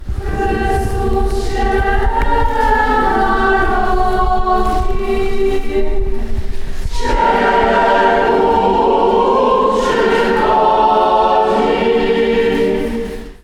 W Ełku w dniach 18-20 stycznia odbywały się warsztaty liturgiczno-muzyczne. Ich zwieńczeniem był koncert uczestników, podczas niedzielnej (20.01.) mszy świętej w parafii pw. św. Jana Apostoła i Ewangelisty.